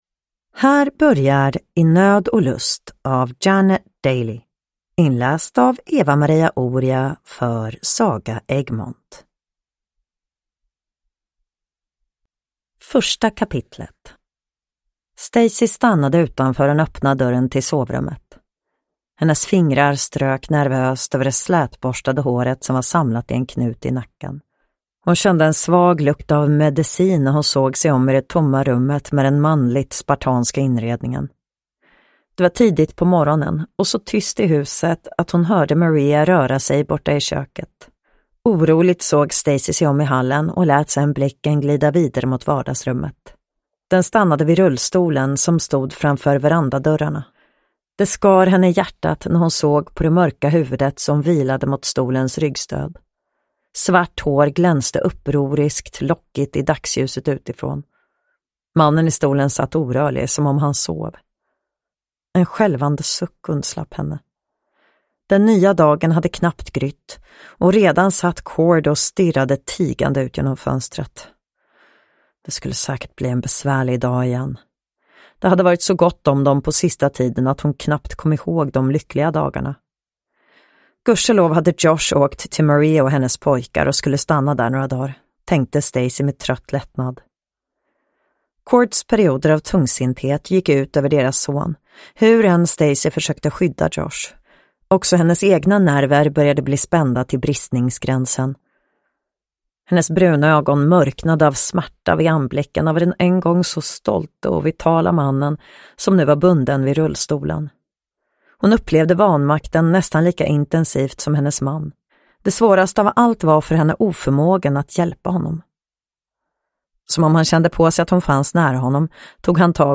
I nöd och lust – Ljudbok